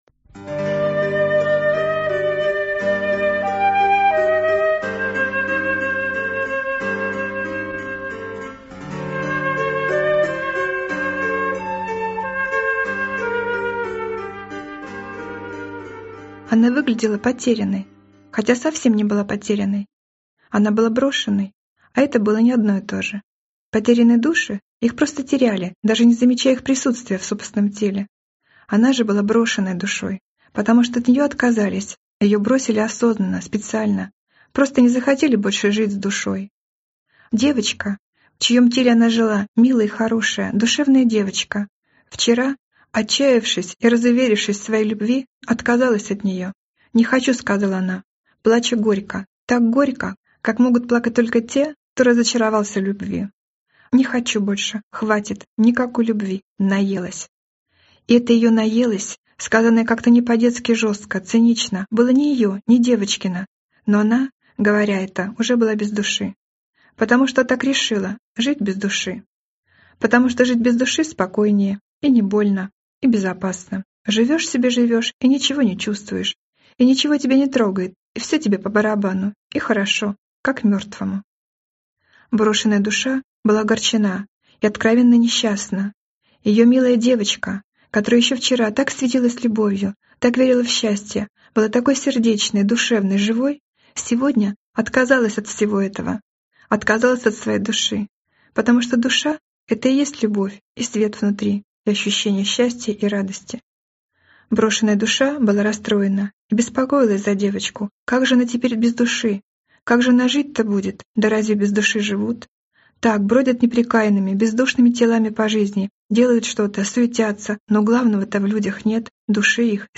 Аудиокнига Живая…